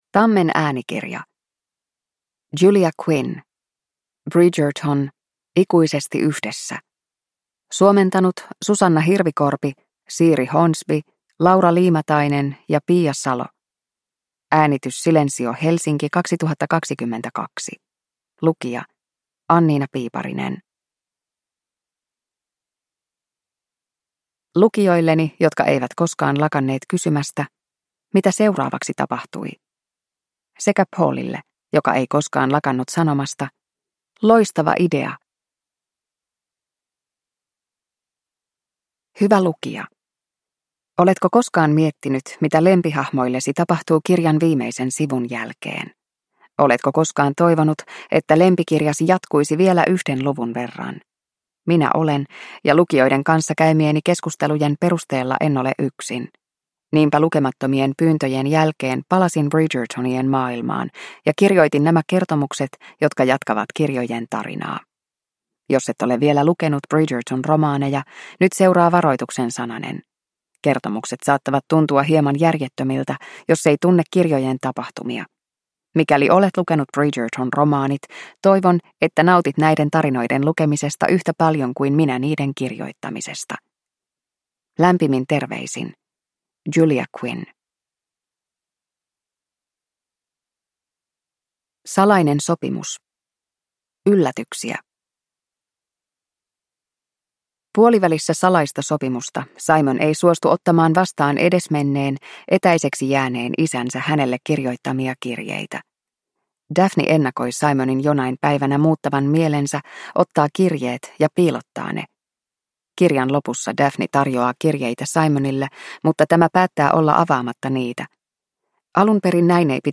Bridgerton: Ikuisesti yhdessä – Ljudbok – Laddas ner